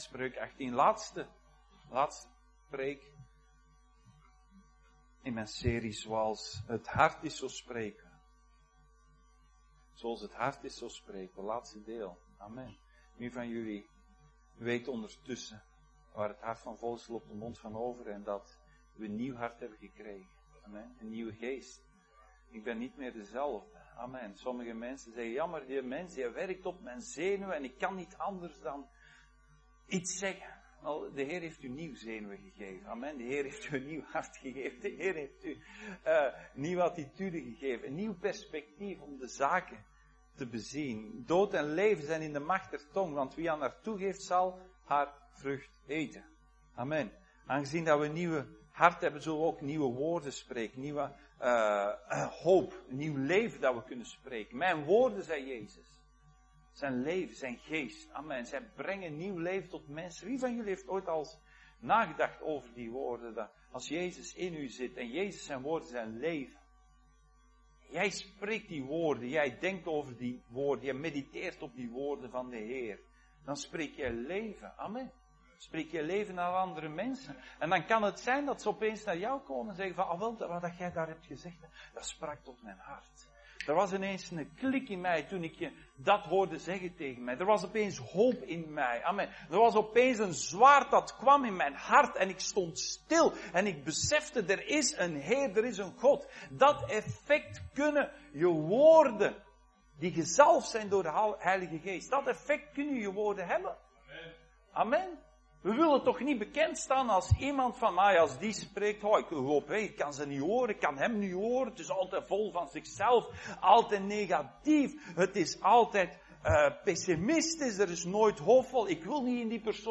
2024 Dienstsoort: Zondag Dienst « Zoals het hart is